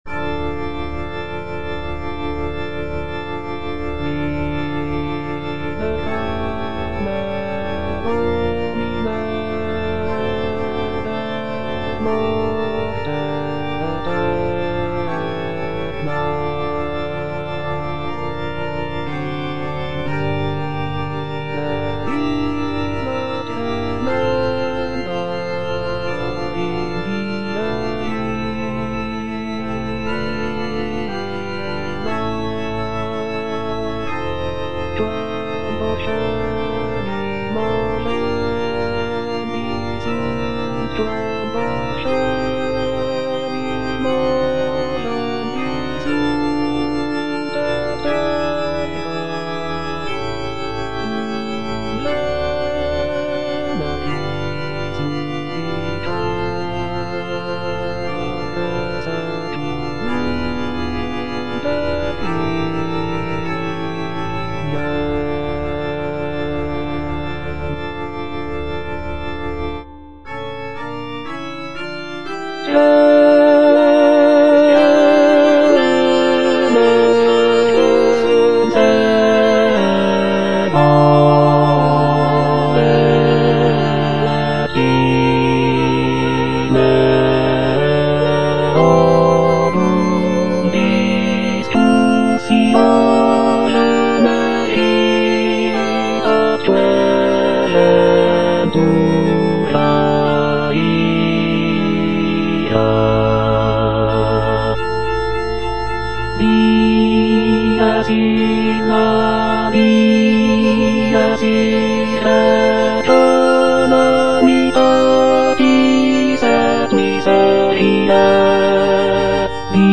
G. FAURÉ - REQUIEM OP.48 (VERSION WITH A SMALLER ORCHESTRA) Libera me (bass I) (Emphasised voice and other voices) Ads stop:
This version features a reduced orchestra with only a few instrumental sections, giving the work a more chamber-like quality.